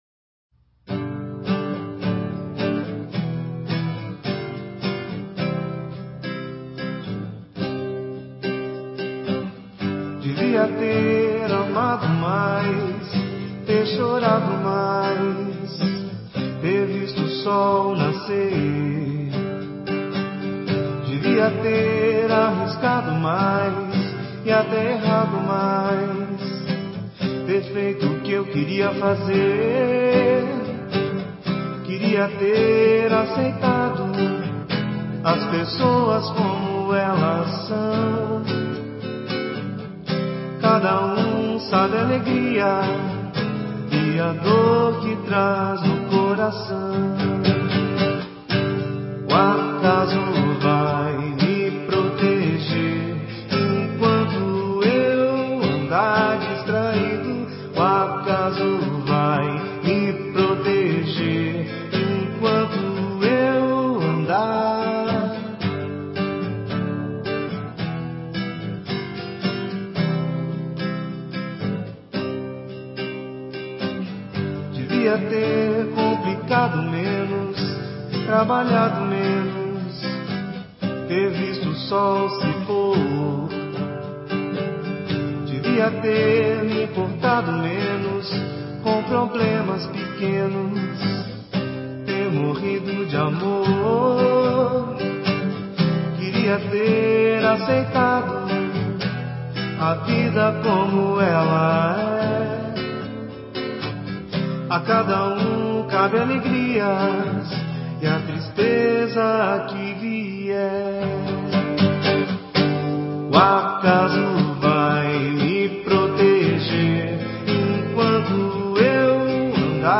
MPB